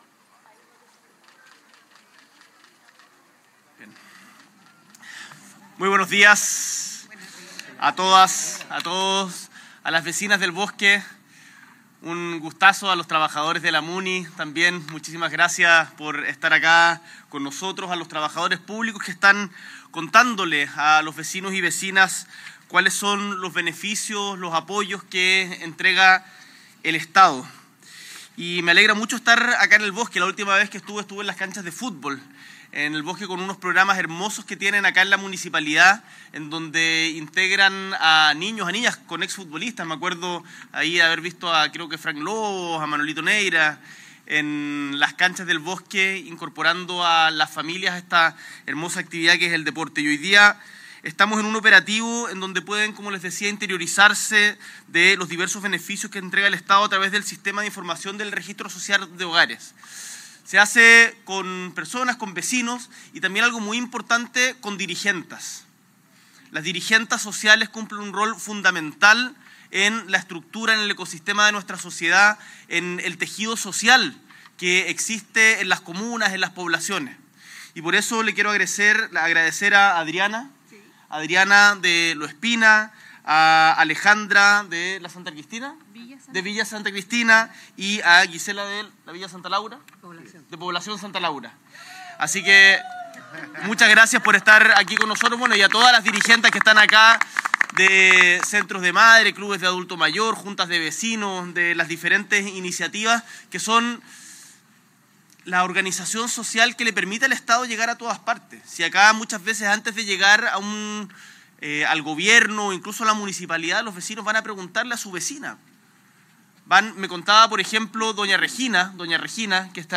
S.E. el Presidente de la República, Gabriel Boric Font, participa de Operativo de actualización del Registro Social de Hogares para acceder a los bonos y beneficios entregados por el Estado, junto a la ministra de Desarrollo Social y Familia, Javiera Toro, la ministra del de Trabajo y Previsión Social, Jeannette Jara, y el alcalde de El Bosque, Manuel Zúñiga.
Discurso